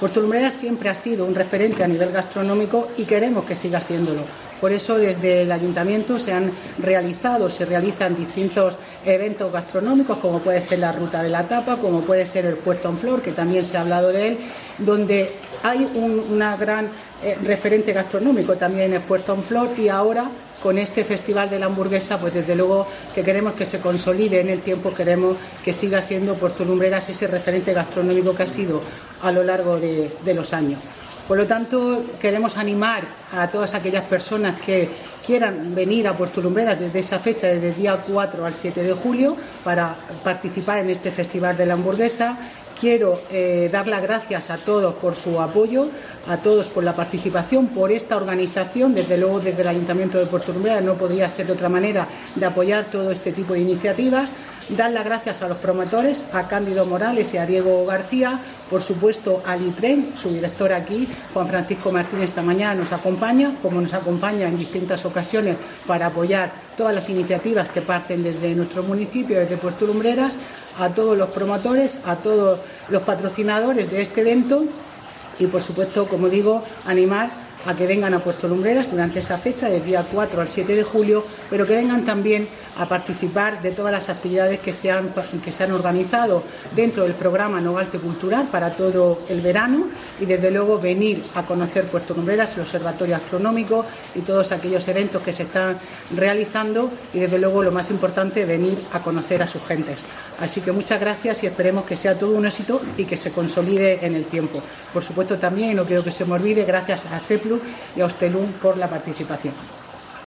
María de los Ángeles Túnez, alcaldesa de Puerto Lumbreras